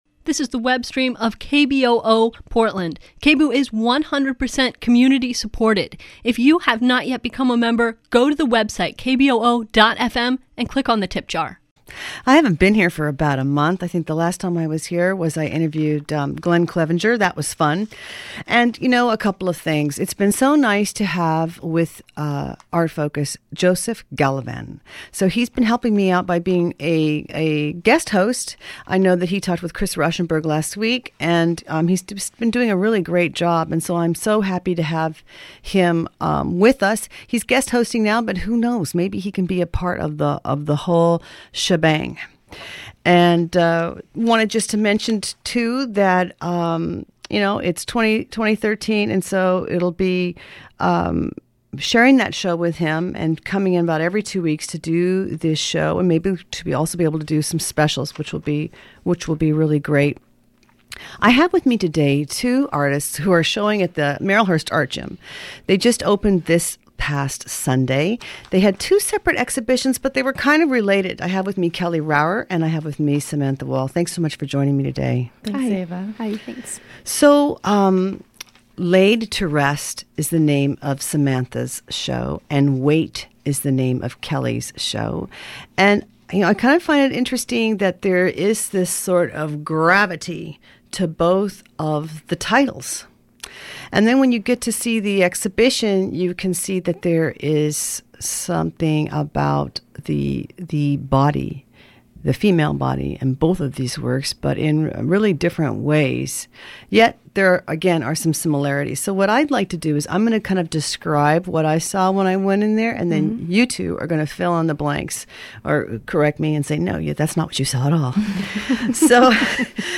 KBOO Subscribe to podcast A radio show about visual art.
Lively voices talking about art, how it's made and sometimes even what it means.